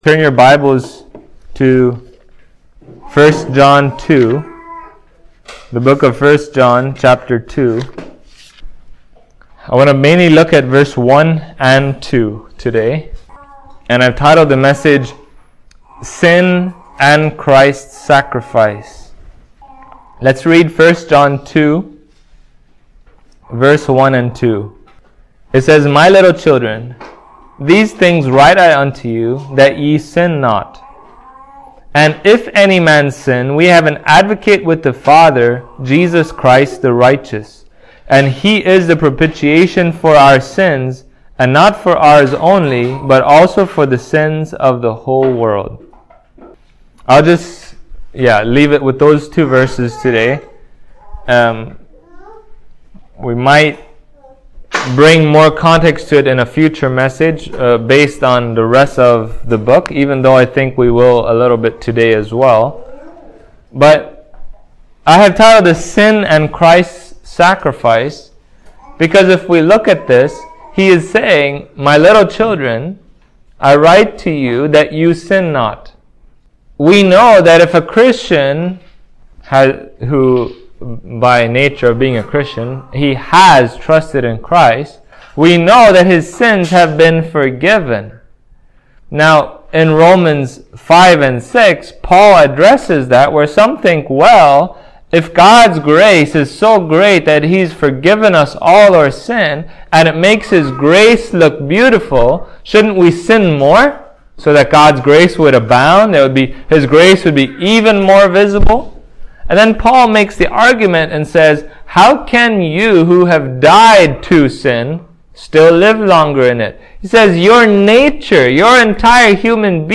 1John 2:1-2 Service Type: Sunday Morning As believers in Christ we will sin at times